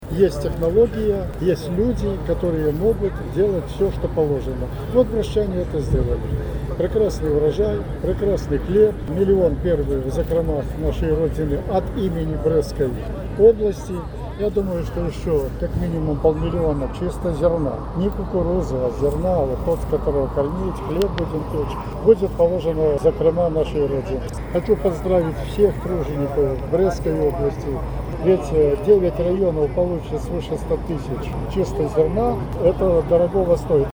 Михаил Русый отметил, что очень многое в уборочной страде зависит от кадров.